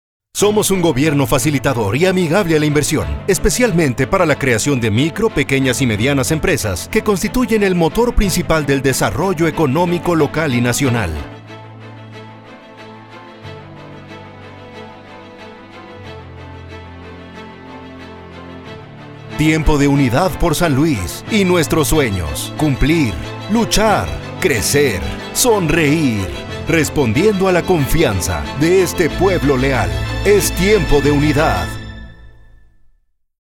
Political Ads
Styles: Warm, Elegant, Natural, Conversational, Sales Man, Corporate, Legal, Deep.
Equipment: Neumann TLM 103, Focusrite Scarlett, Aphex Channel, Source Connect
BaritoneBassDeepLowVery Low